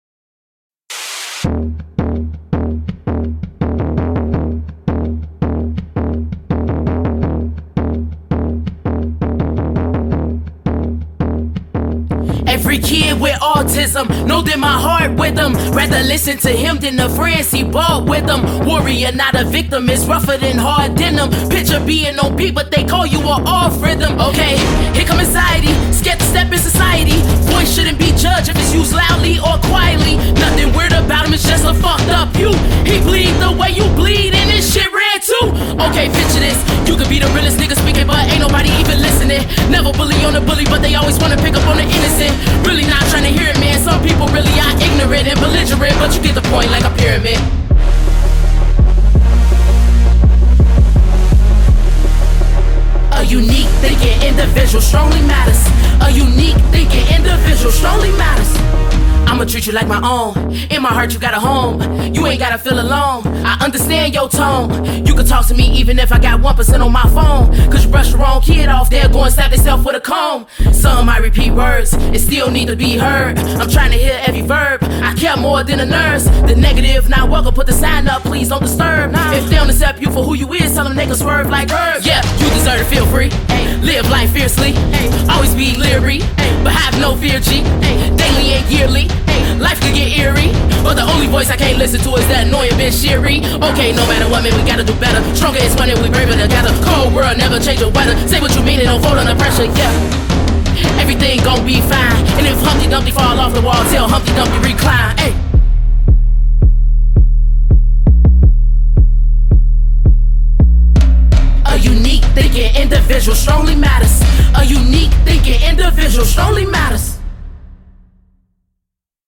BPM83-166
Audio QualityCut From Video